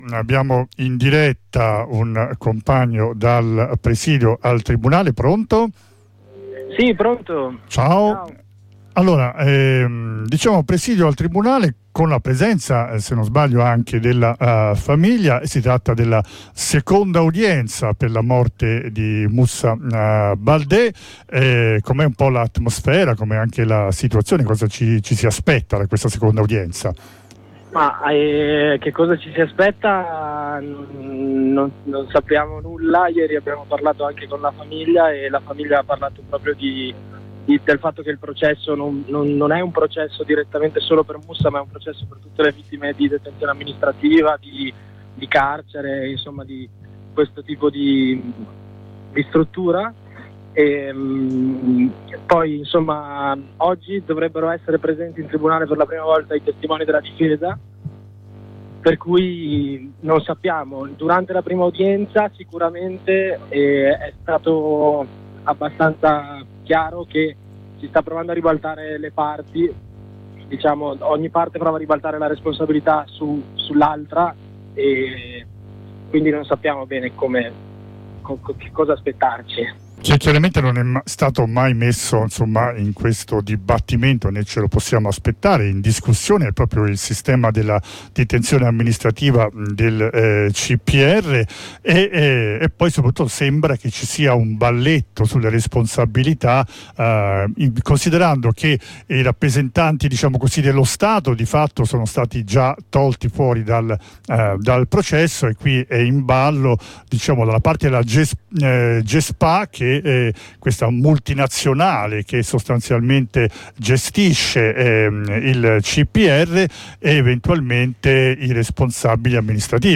Ne parliamo con un compagno presente all’iniziativa .